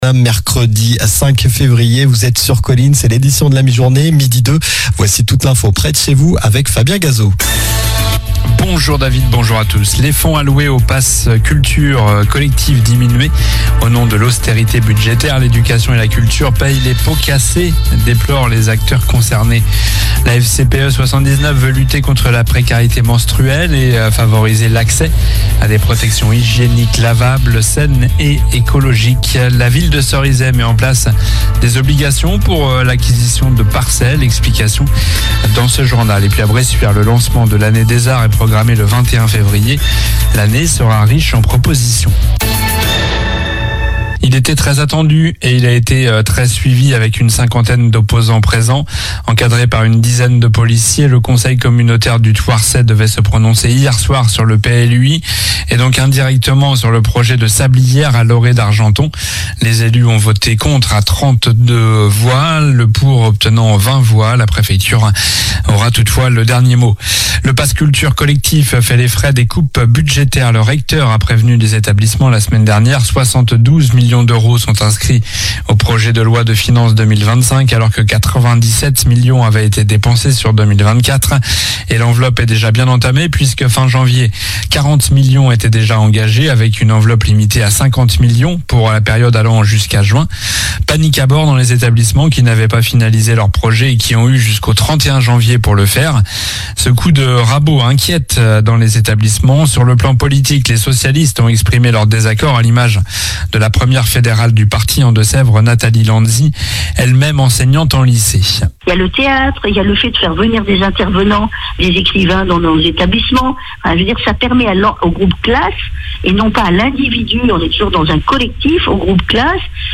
Journal du mercredi 05 février (midi)